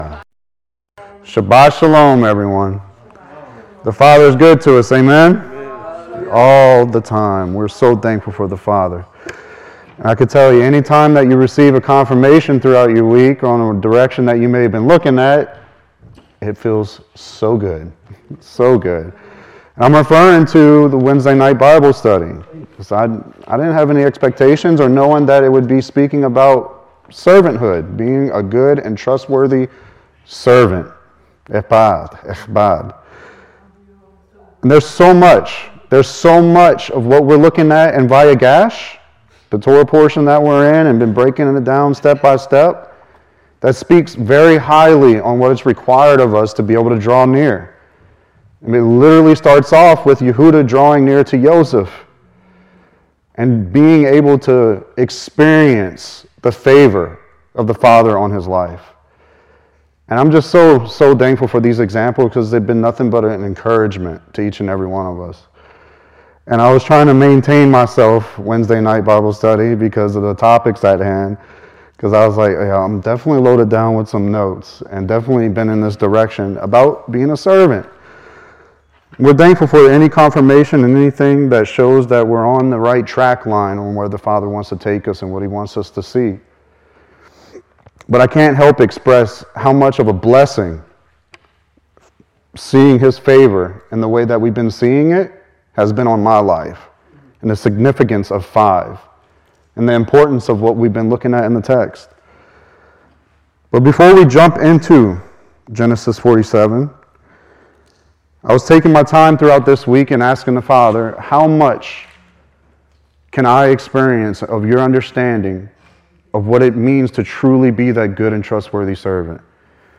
Service Recordings